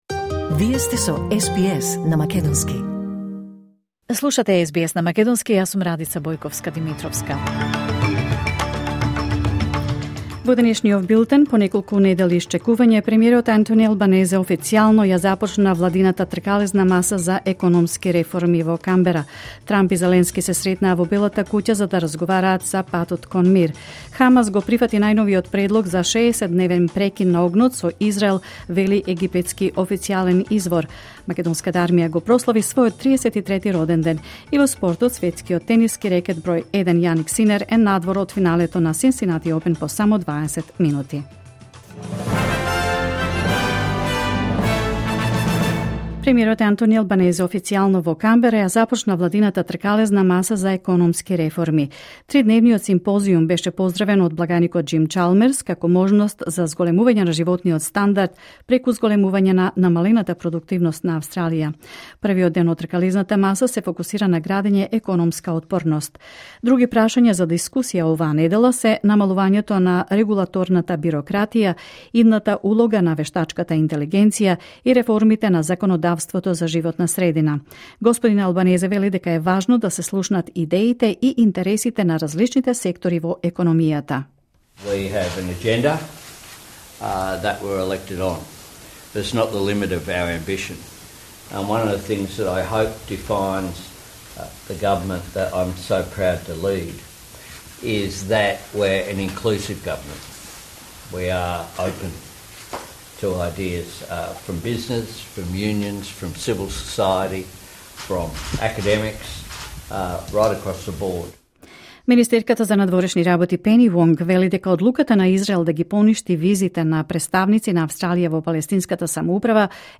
Вести на СБС на македонски 19 август 2025